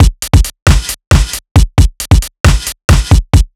Linden Break 135.wav